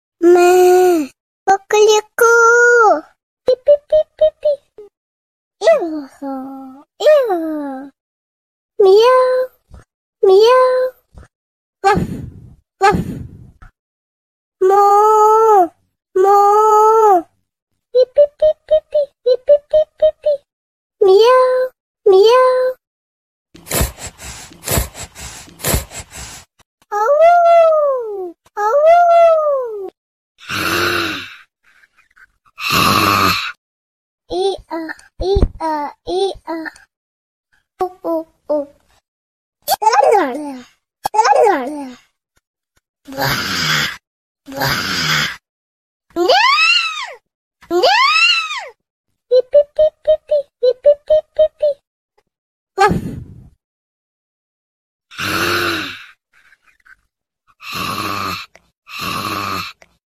cute animal sounds sons sound effects free download